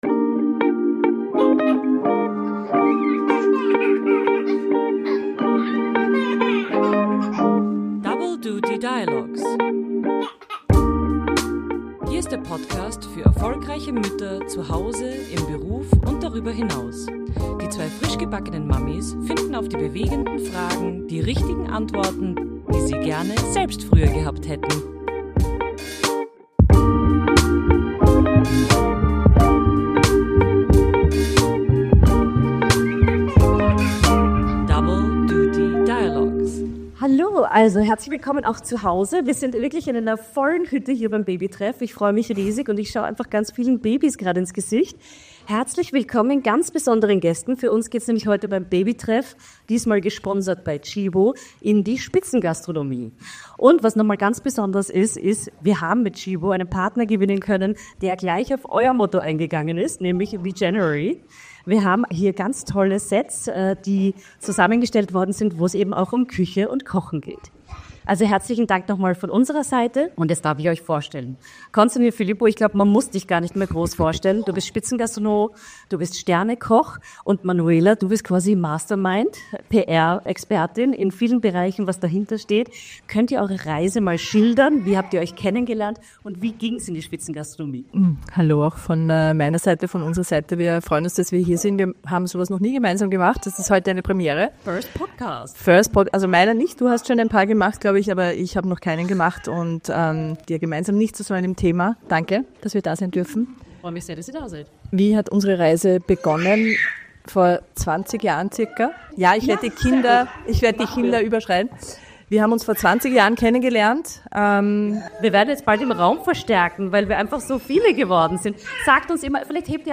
Diese Episode ist ein herzenswarmes Gespräch über Leidenschaft, Partnerschaft und das, was uns wirklich nährt – im Leben, in der Küche und auf dem Weg zu unseren eigenen Sternen.